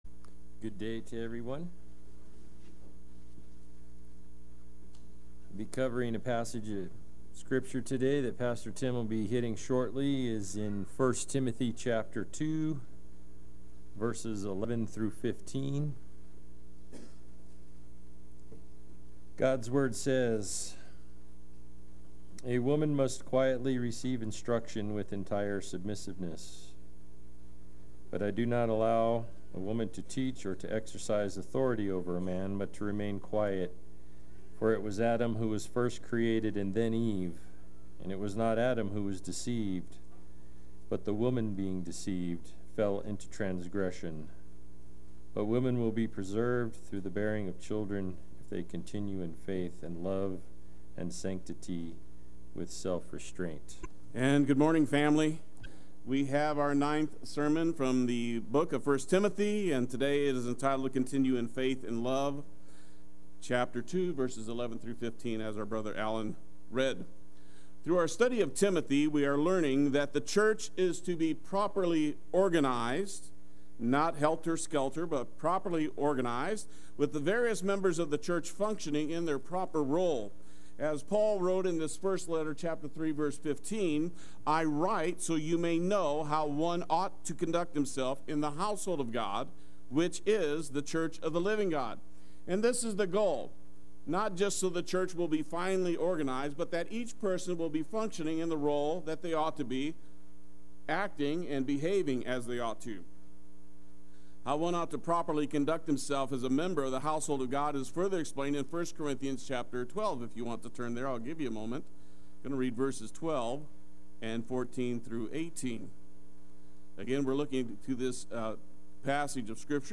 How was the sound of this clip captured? Continue in Faith and Love Sunday Worship